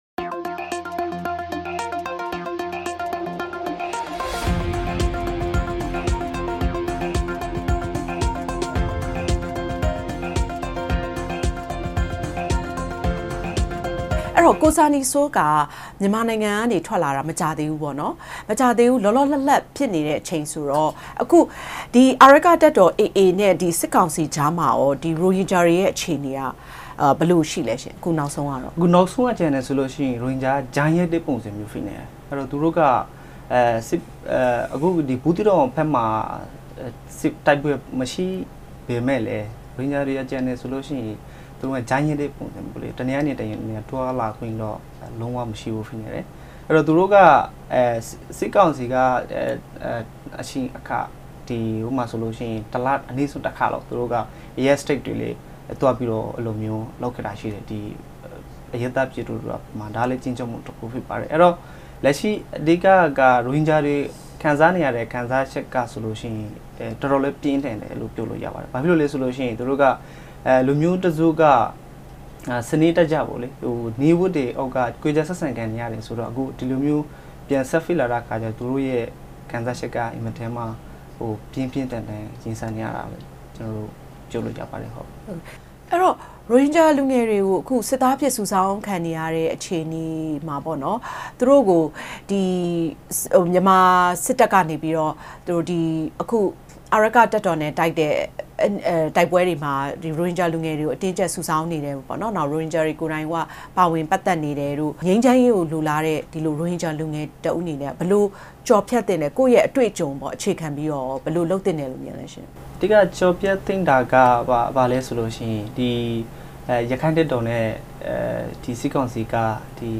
ဗွီအိုအေ စတူဒီယိုမှာ တွေ့ဆုံမေးမြန်းထားပါတယ်။